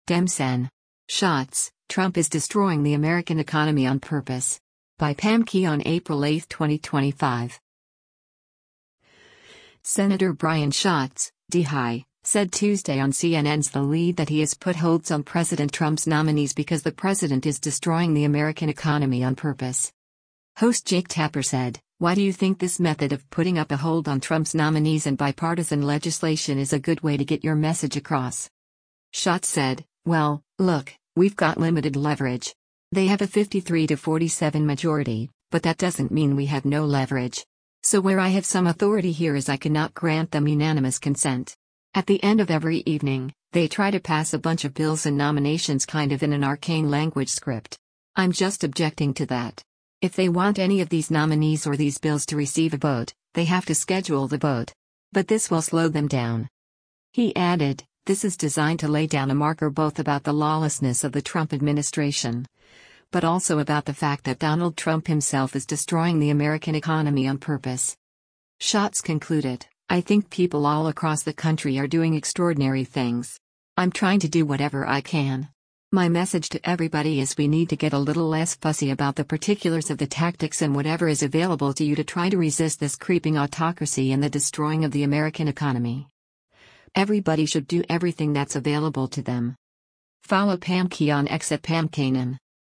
Senator Brian Schatz (D-HI) said Tuesday on CNN’s “The Lead” that he has put holds on President Trump’s nominees because the president “is destroying the American economy on purpose.”
Host Jake Tapper said, “Why do you think this method of putting up a hold on Trump’s nominees and bipartisan legislation is a good way to get your message across?”